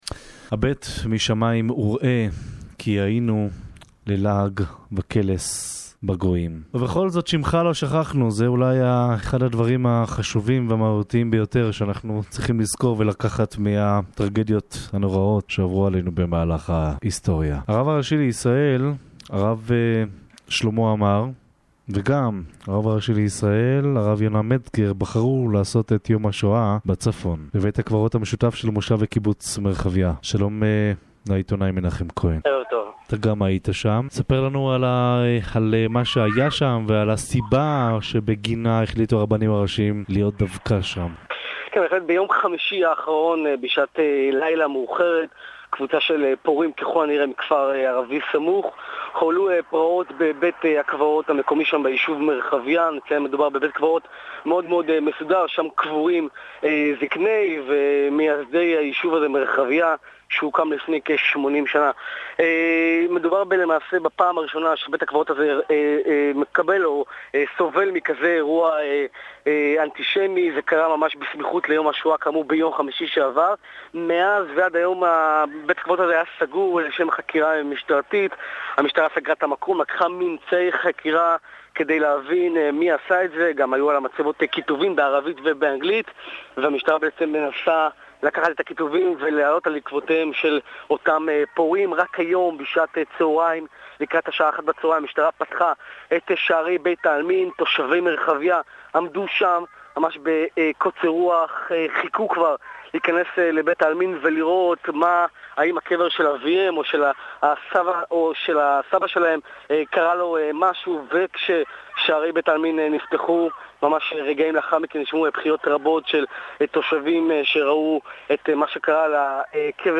בשידור-חי: הגר"ש עמאר מגולל את מה שראו עיניו ● מצמרר